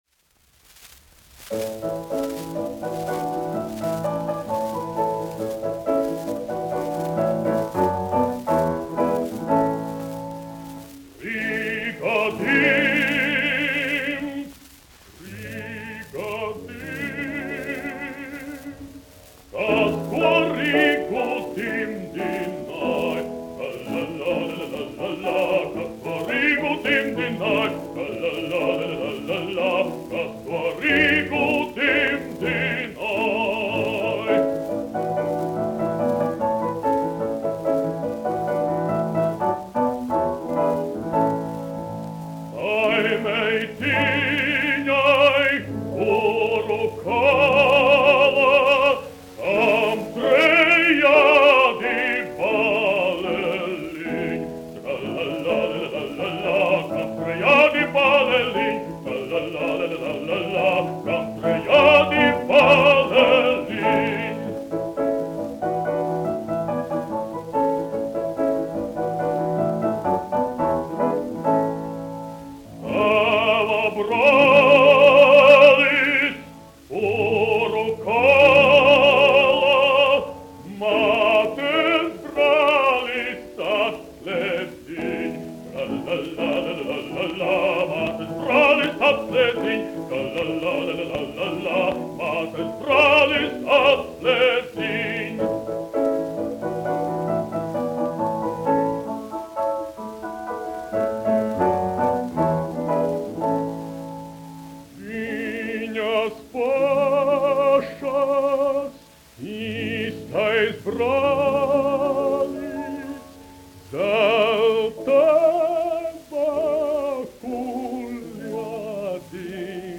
Rīga dimd : tautas dziesma
Jāzeps Vītols, 1863-1948, aranžētājs
Kaktiņš, Ādolfs, 1885-1965, dziedātājs
1 skpl. : analogs, 78 apgr/min, mono ; 25 cm
Latviešu tautasdziesmas
Skaņuplate
Latvijas vēsturiskie šellaka skaņuplašu ieraksti (Kolekcija)